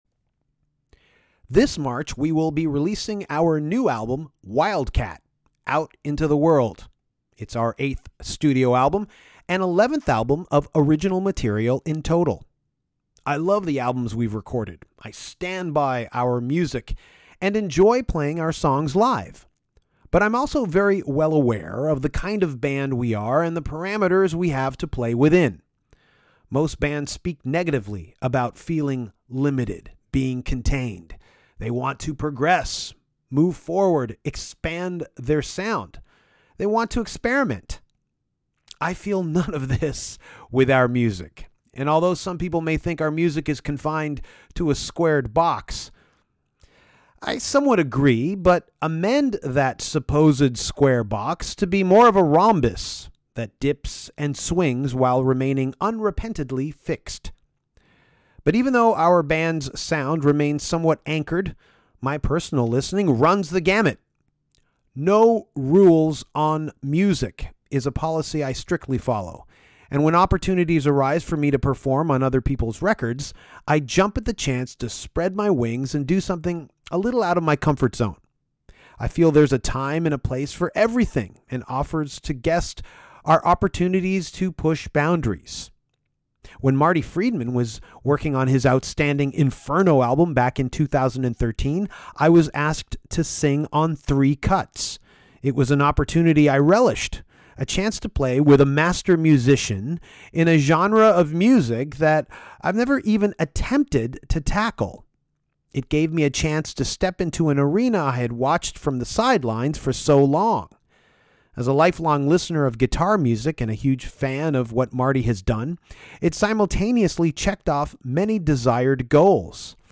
Marty Friedman calls in from Tokyo to discuss his “Inferno” record from 2014 and gives a glimpse of his upcoming record. Talk turns to Shining, Deafheaven and, of course, Kiss.